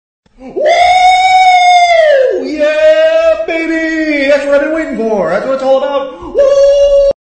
Woo, Yeah Baby! Meme sound effects free download
Woo, Yeah Baby! - Meme Sound Effect